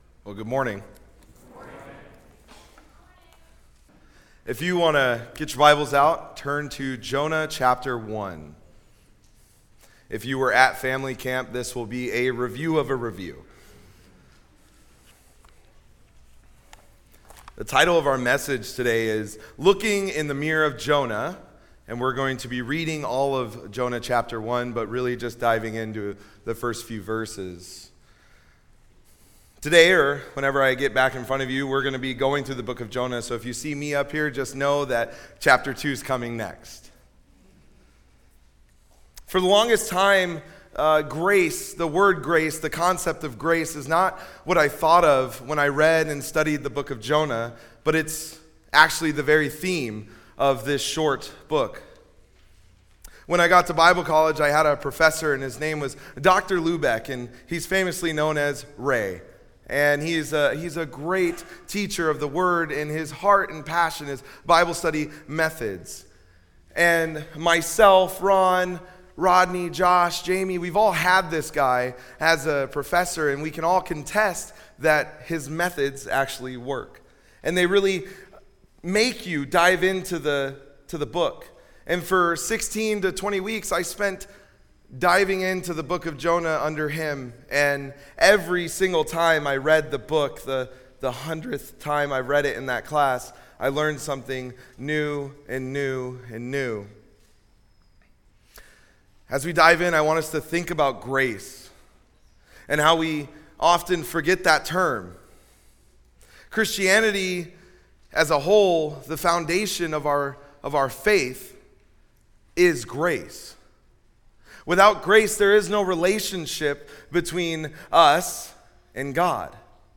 Looking in the Mirror of Jonah (Jonah 1:1-17) – Mountain View Baptist Church
Topical Message